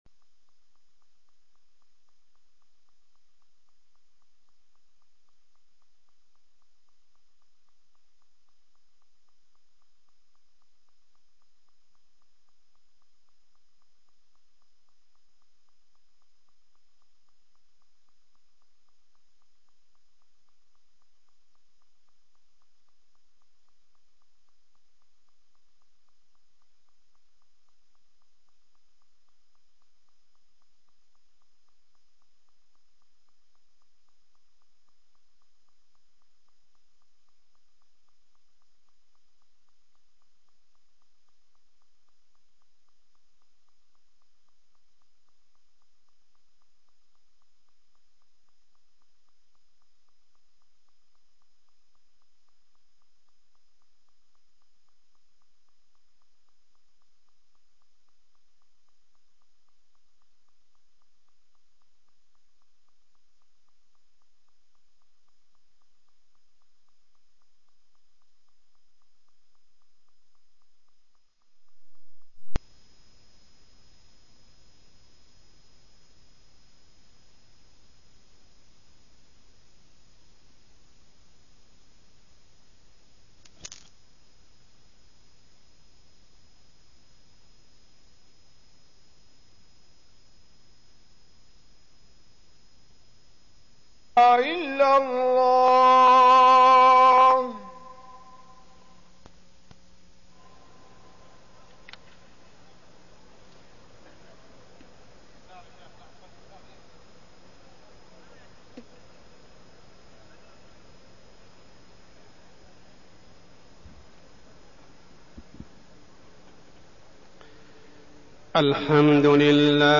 تاريخ النشر ٨ شعبان ١٤١٩ هـ المكان: المسجد الحرام الشيخ: عمر السبيل عمر السبيل حلمه صلى الله عليه وسلم